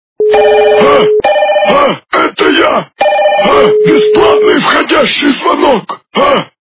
» Звуки » Смешные » Это я. - Бесплатный входящий звонок
При прослушивании Это я. - Бесплатный входящий звонок качество понижено и присутствуют гудки.